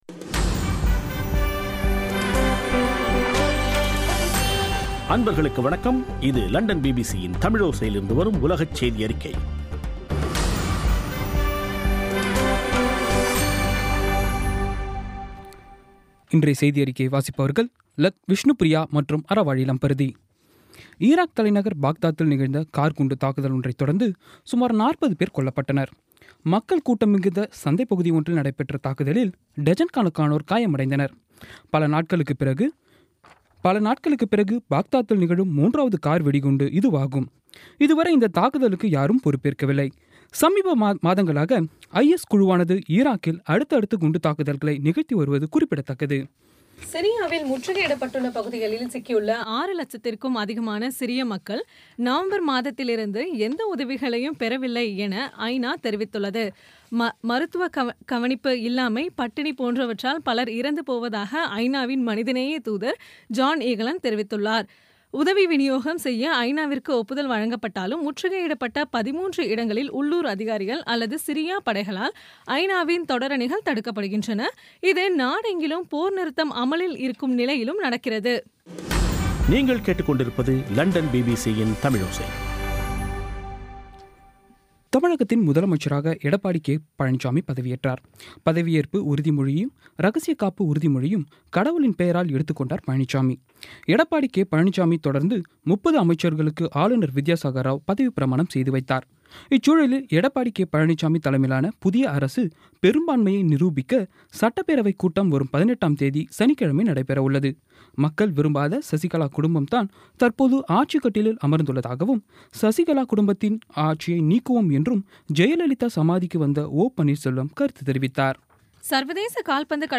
பிபிசி தமிழோசை செய்தியறிக்கை (16/02/17)